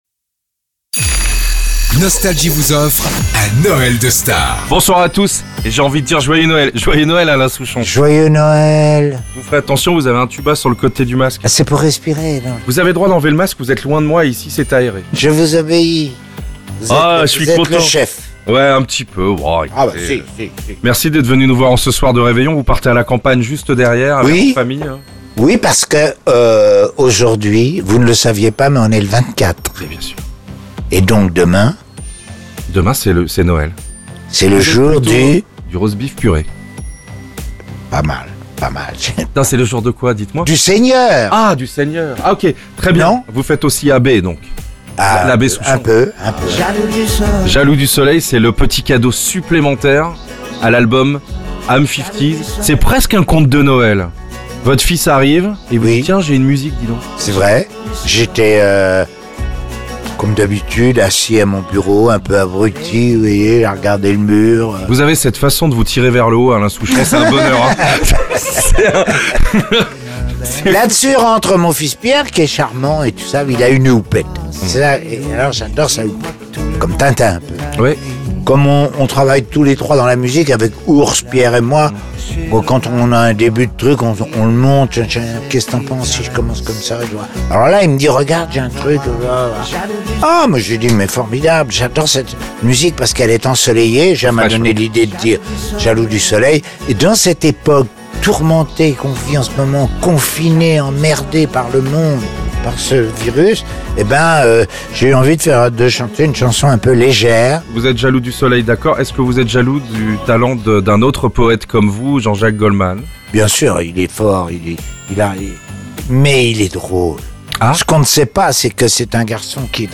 Erweiterte Suche Noël de Stars avec Alain Souchon ! 16 Minuten 22.02 MB Podcast Podcaster Les interviews Les plus grands artistes sont en interview sur Nostalgie.